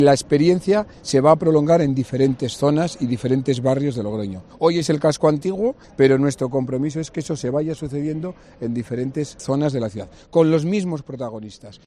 Conrado Escobar, alcalde de Logroño